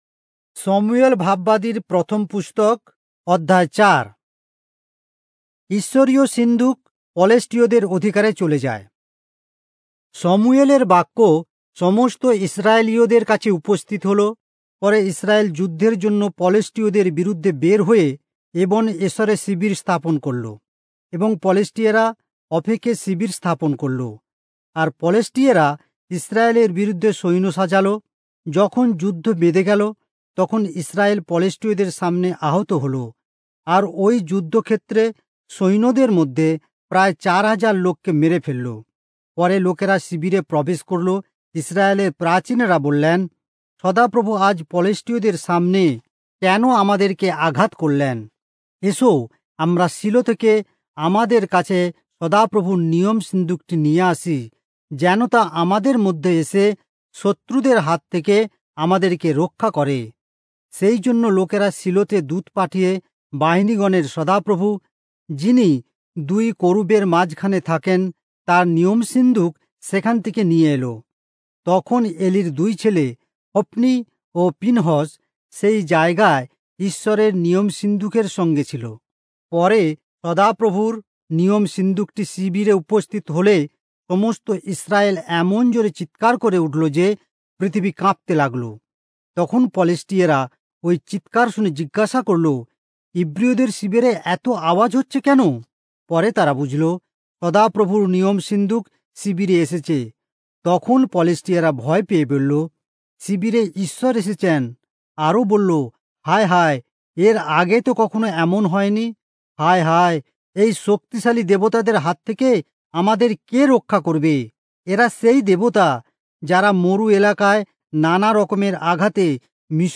Bengali Audio Bible - 1-Samuel 5 in Irvbn bible version